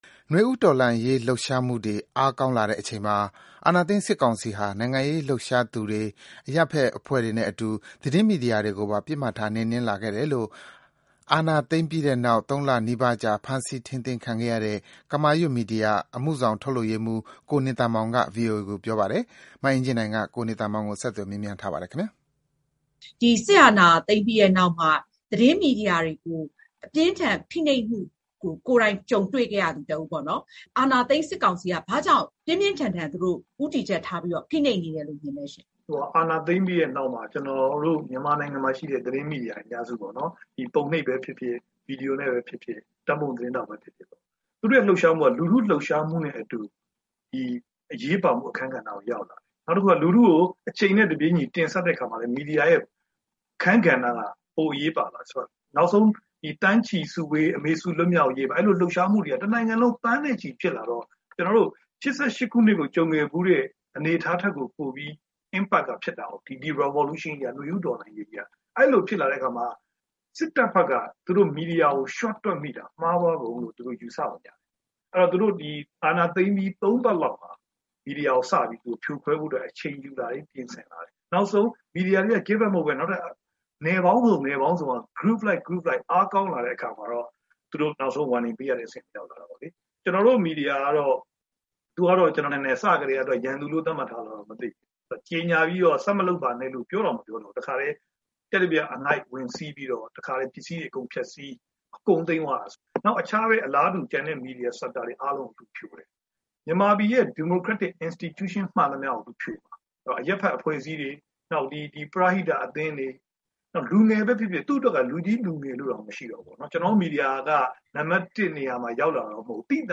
VOA က ဆက်သွယ်မေးမြန်းထားပါတယ်။